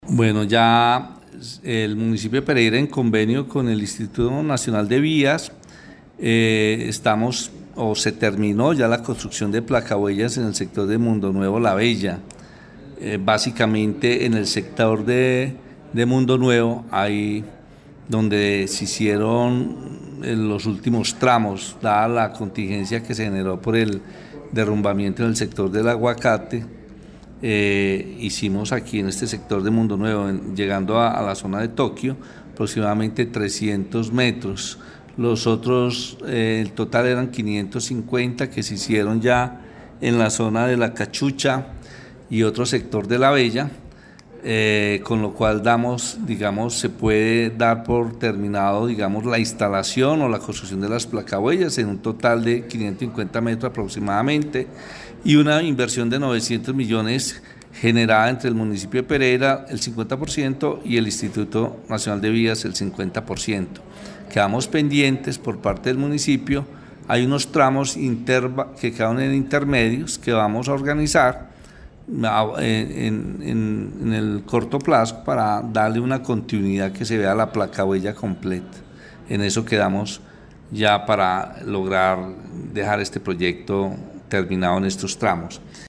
AUDIO_MILTON_HURTADO_SECRETARIO_DE_INFRAESTRUCTURA.03.mp3